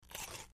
Juicy, Crunchy Bites Into Vegetable, X5